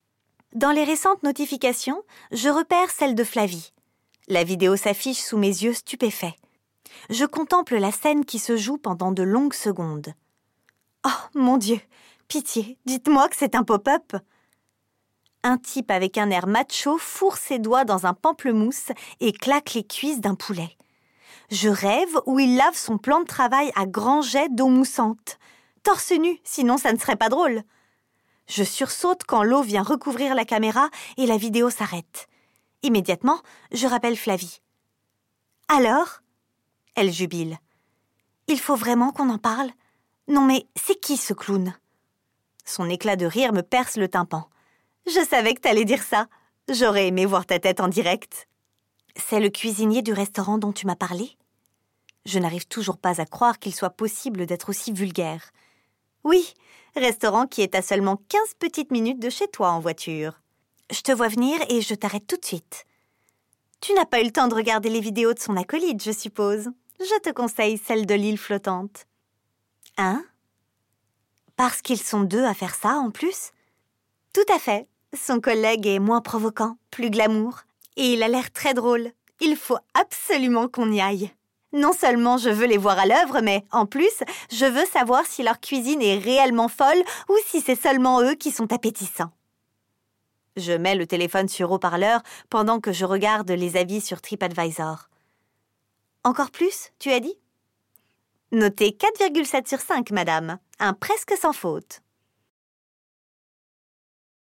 My voice is young, dynamic, sensual, soft, luminous and smiling… Just like me !
AUDIOBOOK